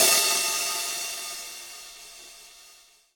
DUBHAT-21.wav